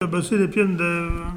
Nieul-sur-l'Autise
Catégorie Locution